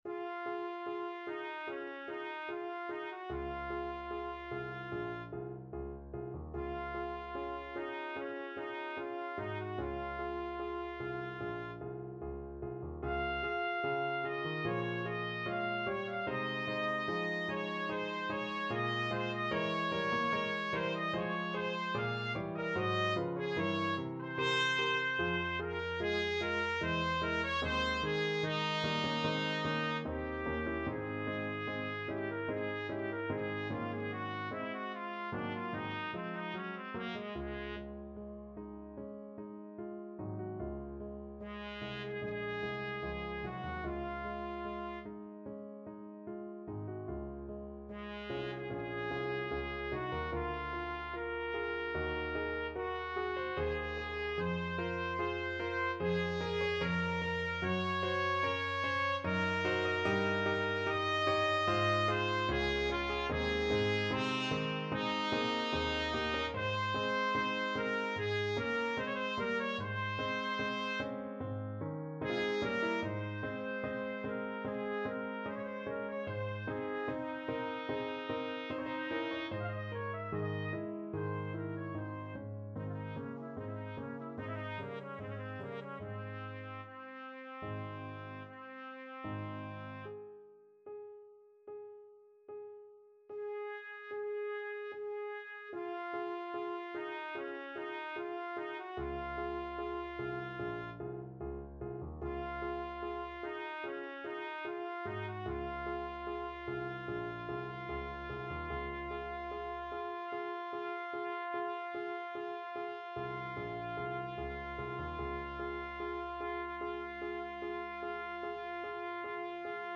Classical Dvořák, Antonín Serenade for Strings, Op. 22, First Movement Trumpet version
Trumpet
Db major (Sounding Pitch) Eb major (Trumpet in Bb) (View more Db major Music for Trumpet )
~ = 74 Moderato
4/4 (View more 4/4 Music)
Ab4-F6
Classical (View more Classical Trumpet Music)